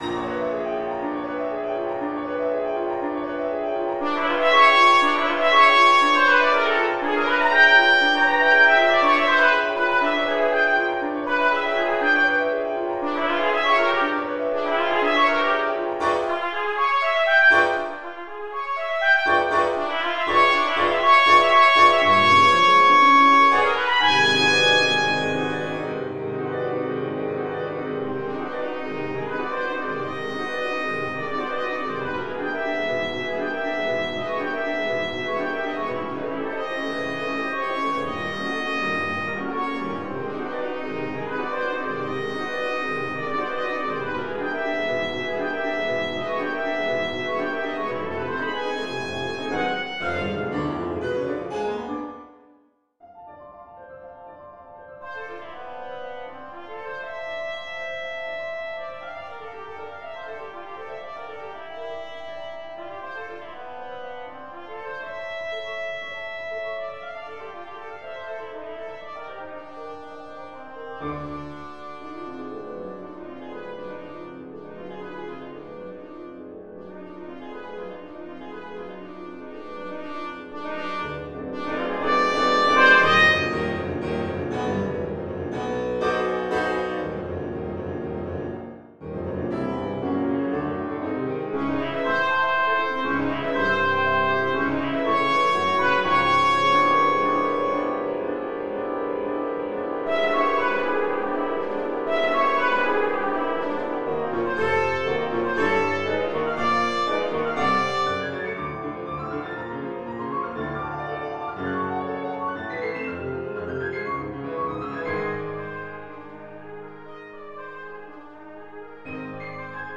Genre: Duet for Trumpet & Piano
Piano